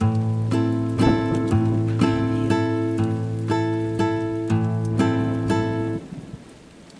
ریتم 3/4 گیتار نایلون | اجرای ریتم 3/4 والس گیتار کلاسیک در تمام آکوردهای مینور و ماژور به صورت لوپ برای استفاده در تنظیم و تمرین نوازندگی
ریتم ۳/۴ گیتار نایلون
ریتم ۳/۴ گیتار کلاسیک با کیفیت بسیار بالا و اجرای زیبا به صورت Pickstyle
Guitar-3-4-Walts-Pick-120BPM-Am-preview.mp3